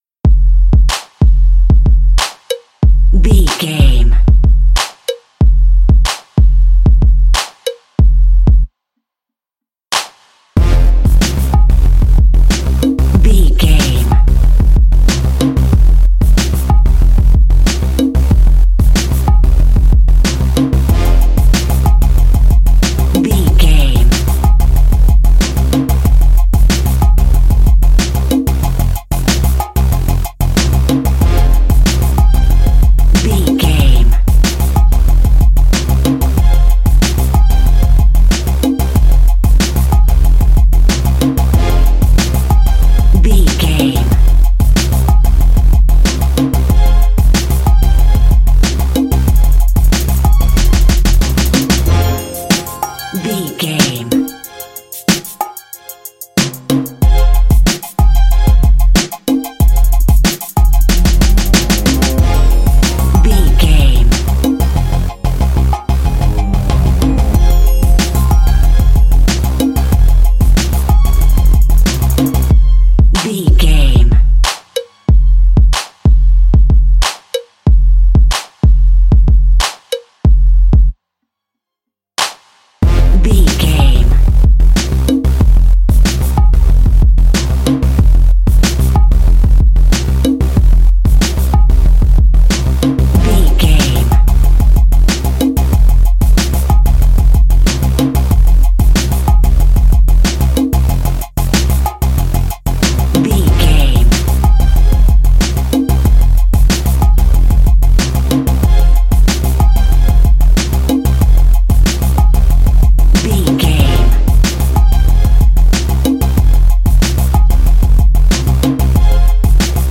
Ionian/Major
D
synthesiser
drum machine
funky